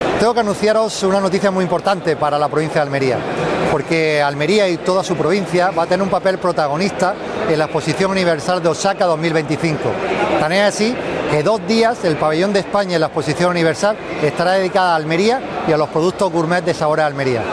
El presidente de Diputación anuncia la presencia de la marca gourmet en la Exposición Universal que celebra Japón para proyectar los productos almerienses entre consumidores de los cinco continentes
08-04_presidente_expo_universal_osaka_sabores....mp3